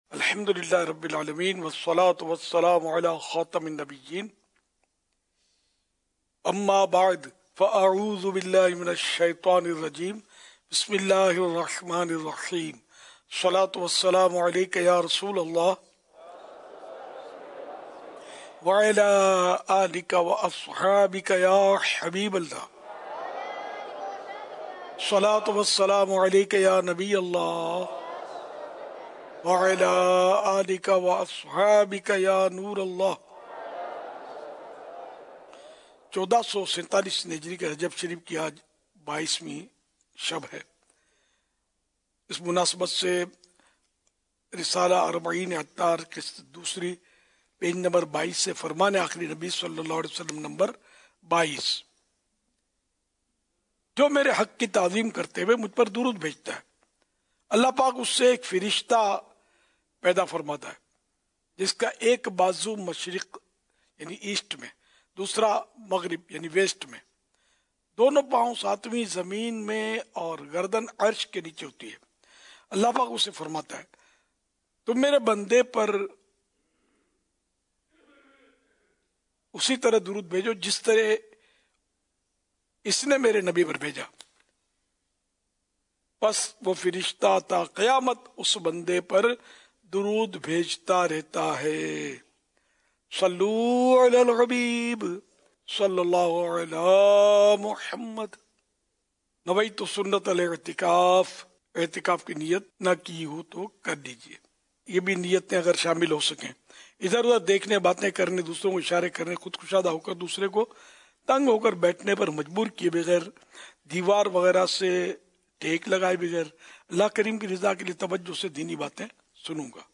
محفلِ نعت بسلسلہ عرسِ حضرت امیر معاویہ رضی اللہ عنہ Time Duration